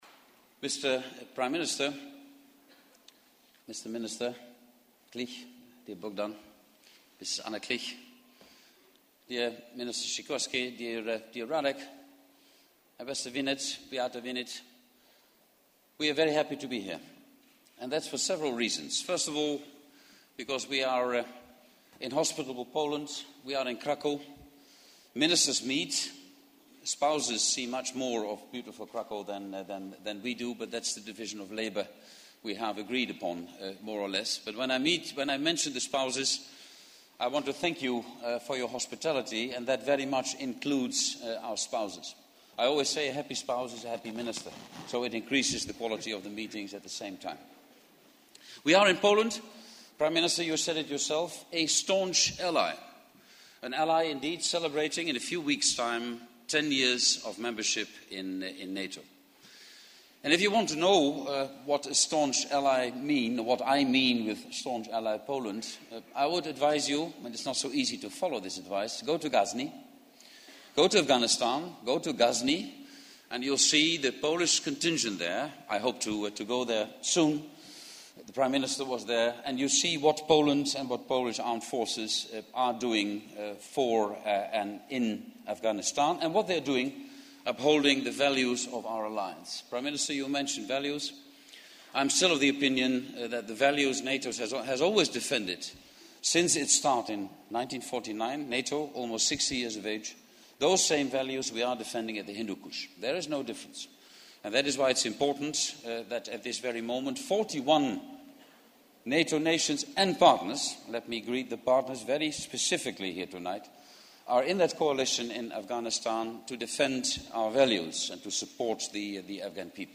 Remarks by NATO Secretary General Jaap de Hoop Scheffer at the cultural event at the National museum